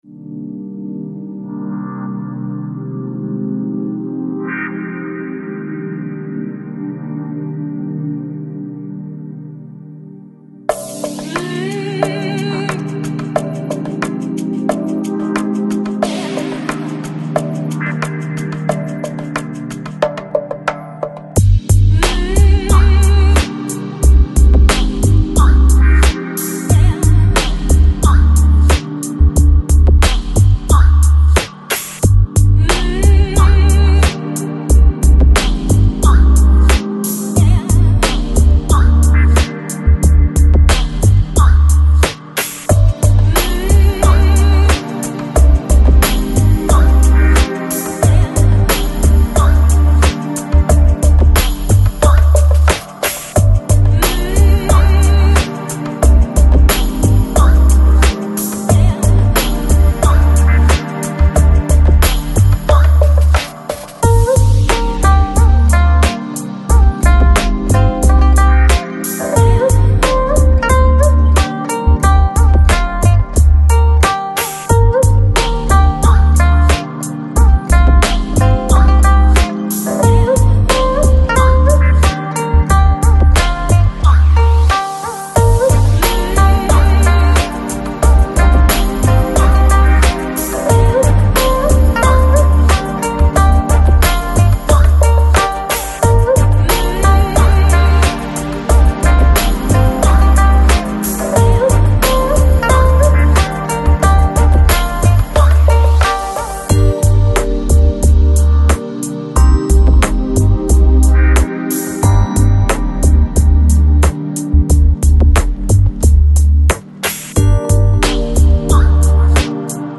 Жанр: Electronic, Chill Out, Lounge, Downtempo